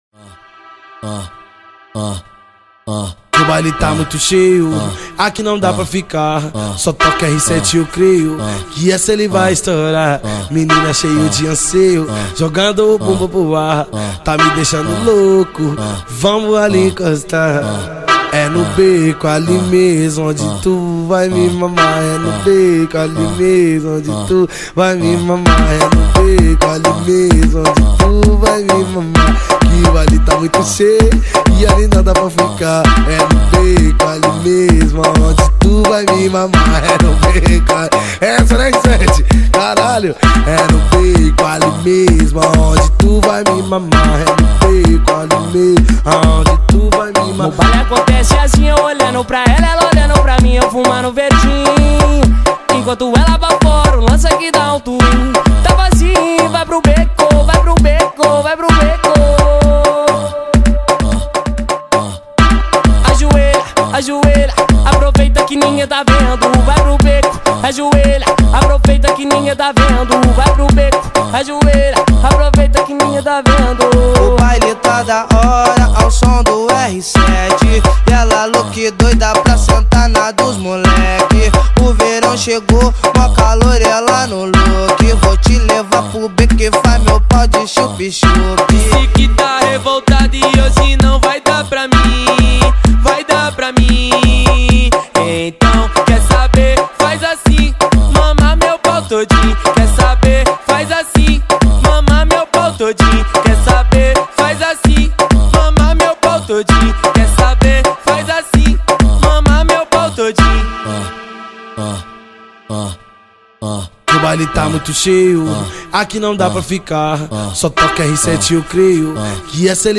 2024-09-30 20:53:58 Gênero: Funk Views